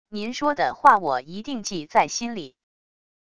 您说的话我一定记在心里wav音频生成系统WAV Audio Player